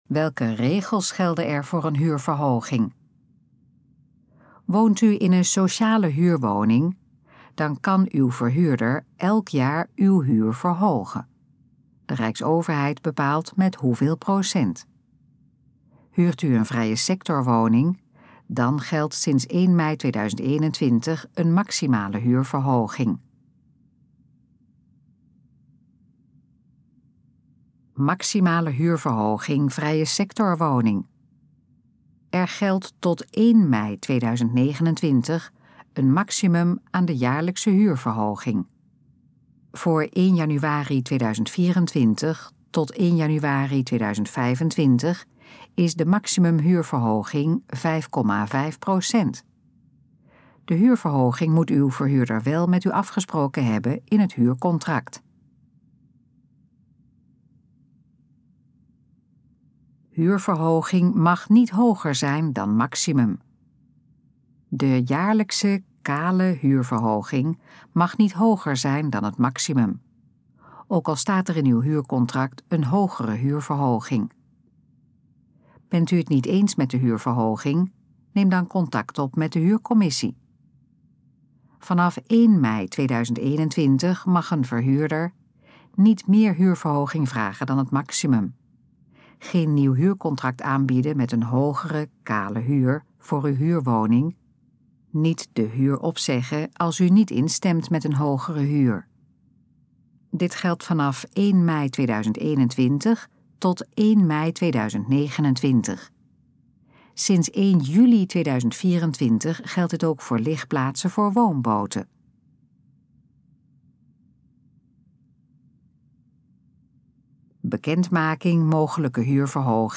Gesproken versie van Welke regels gelden er voor een huurverhoging?
Dit geluidsfragment is de gesproken versie van de pagina Welke regels gelden er voor een huurverhoging?